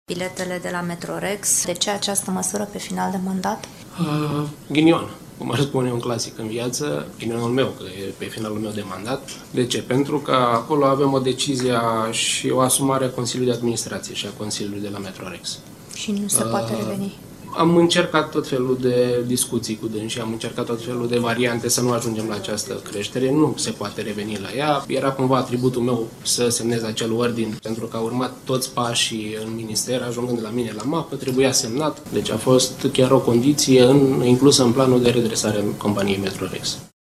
Întrebat de jurnaliști care a fost motivul pentru care a luat această măsură chiar înainte de a-și da demisia, Șerban a susținut că aceasta a fost chiar o condiție pentru avizarea bugetului de redresare a Metrorex pentru acest an.